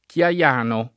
[ k L a L# no ]